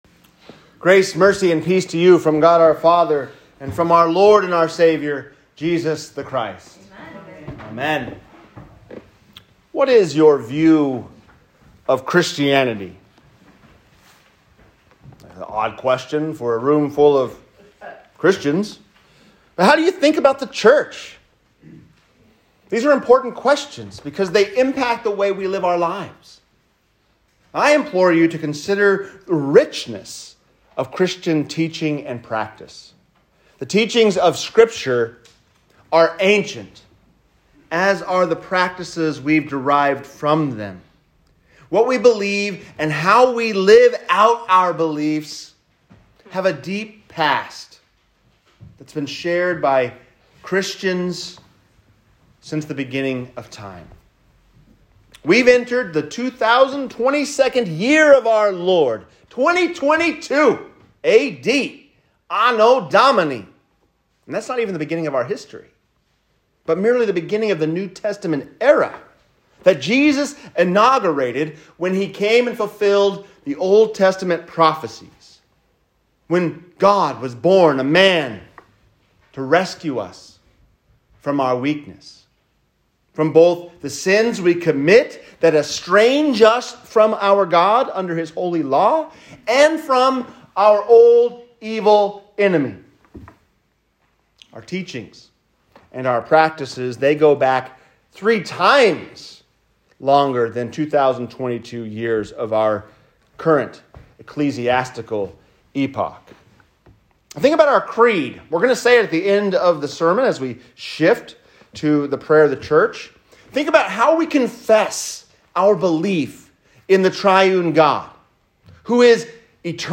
The Ancient Scriptures | Sermon
1-9-22-sermon_baptism-of-our-lord.m4a